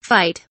fight kelimesinin anlamı, resimli anlatımı ve sesli okunuşu